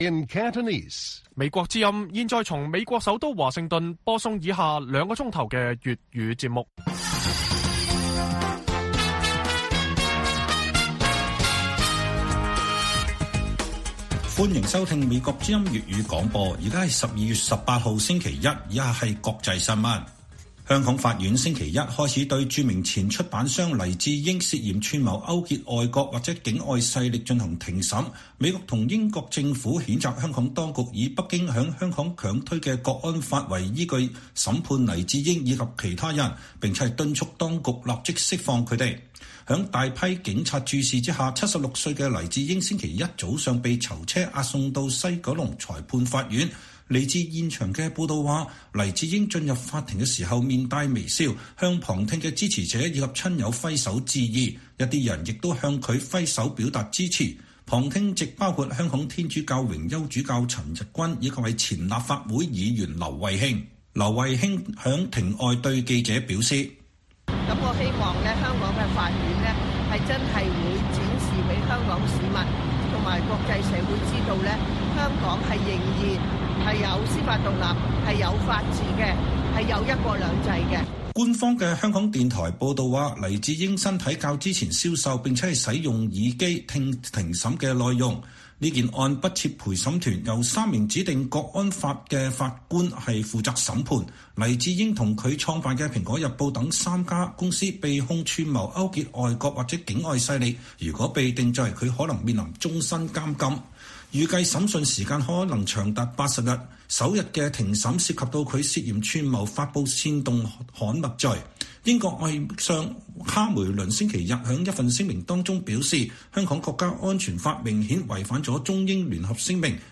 粵語新聞 晚上9-10點: 黎智英國安案開審